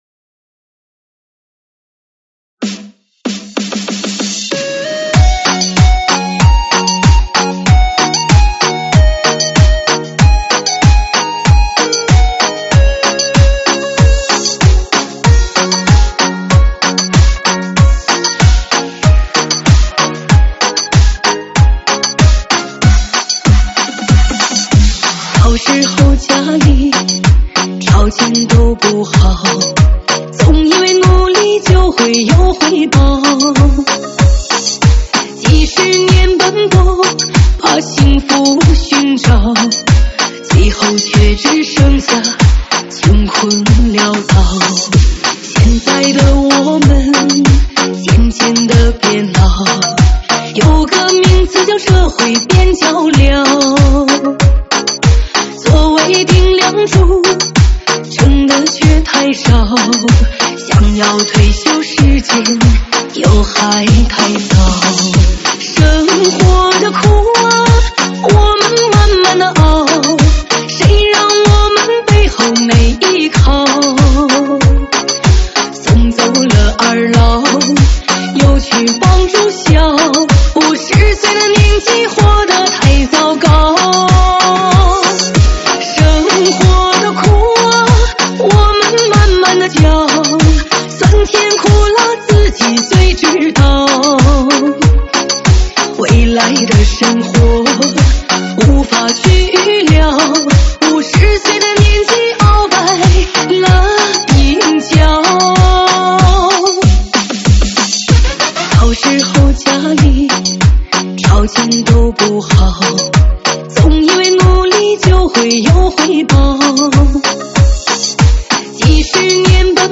收录于(吉特巴)